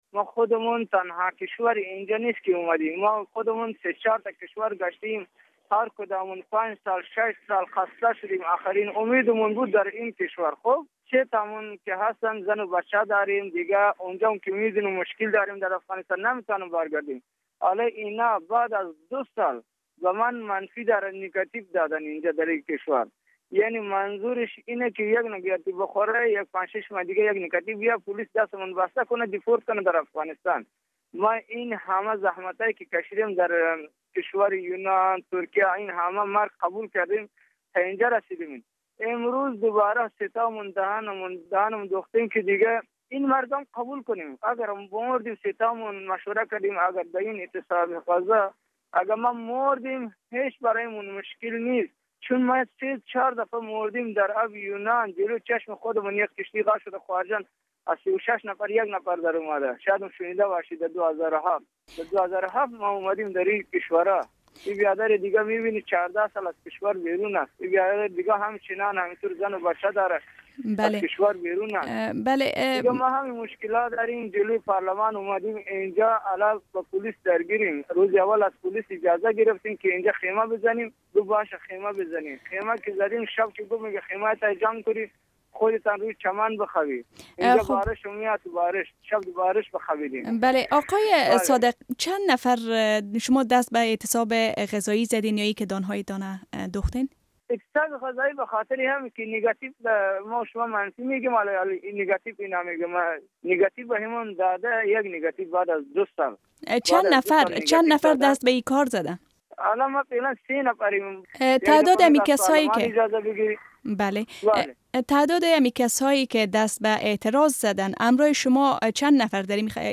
مصاحبه با یک پناهجوی افغان که در فنلند دهن اش را دوخته است